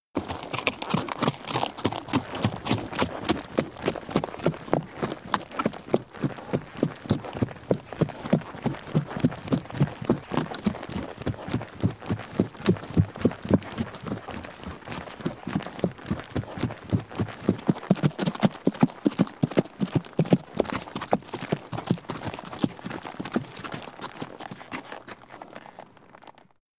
sound-of-walking-horses